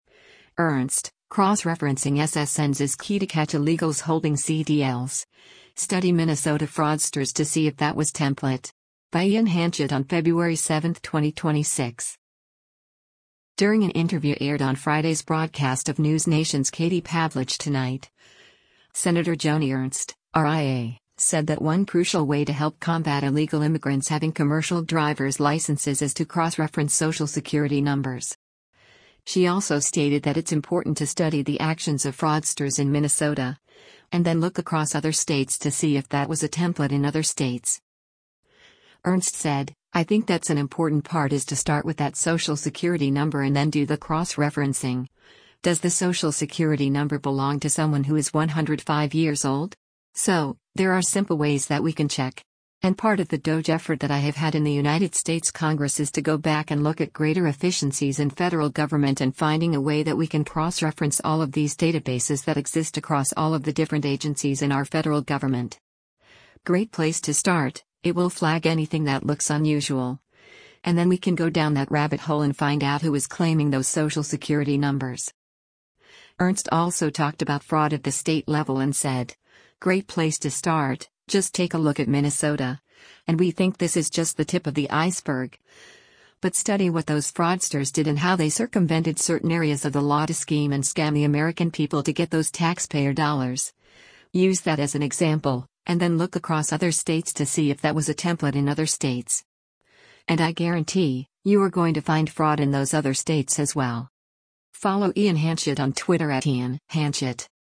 During an interview aired on Friday’s broadcast of NewsNation’s “Katie Pavlich Tonight,” Sen. Joni Ernst (R-IA) said that one crucial way to help combat illegal immigrants having commercial driver’s licenses is to cross-reference Social Security numbers.